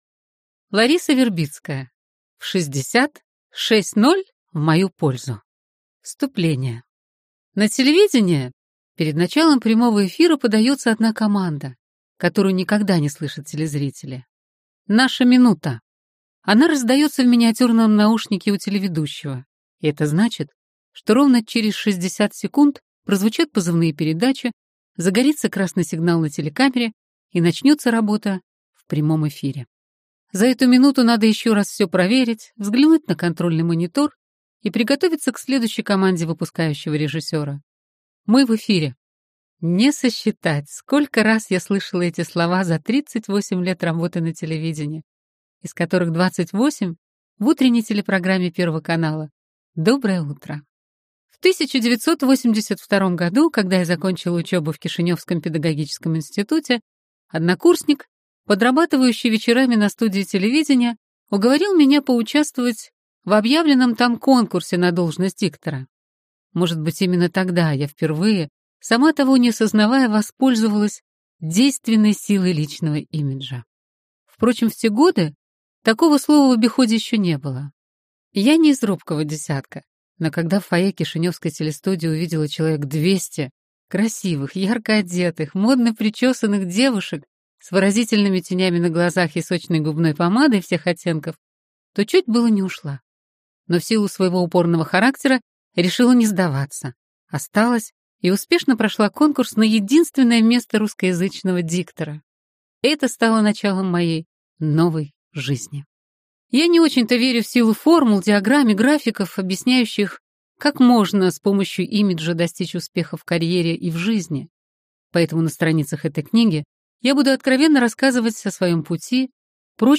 Аудиокнига В 60 – 6:0 в мою пользу | Библиотека аудиокниг